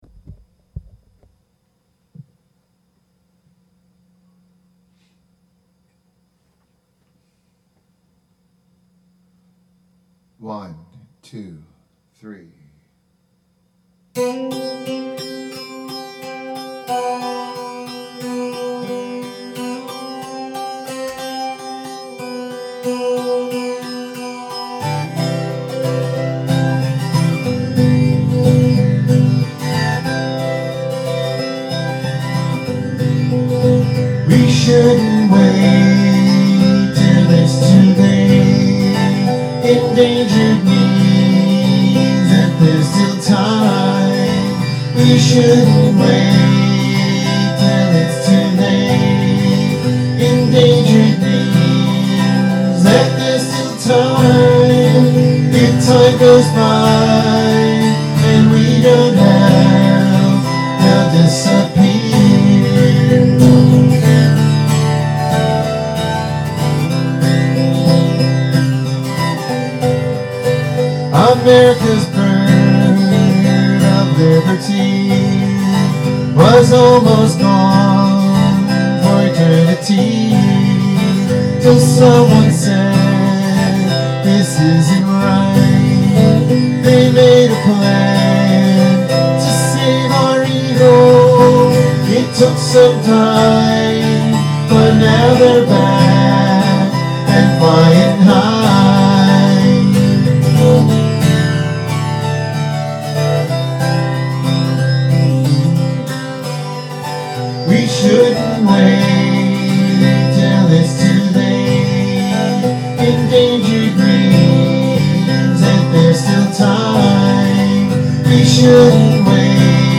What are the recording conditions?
This song is a live unedited recording from my music room. It is dedicated to my favorite organization - The World Wild Life Federation, and their efforts to save endangered species.